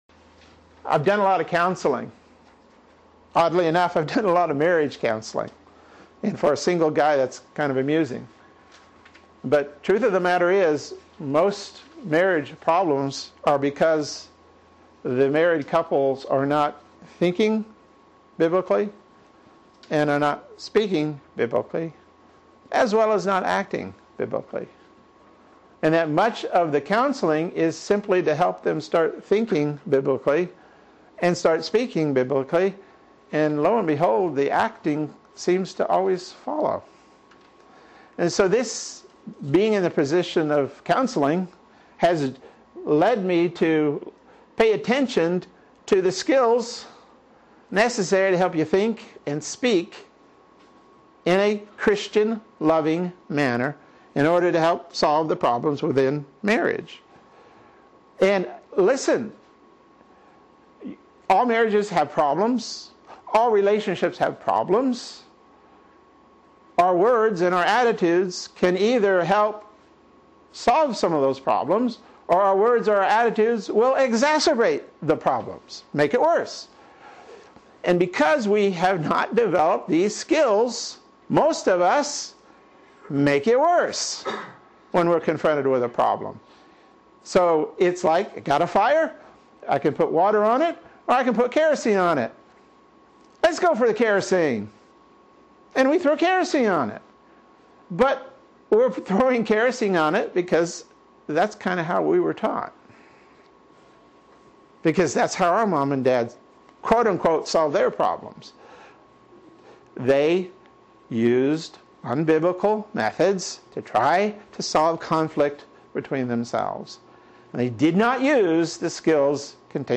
Sermons | Reformed Presbyterian Church of Ocala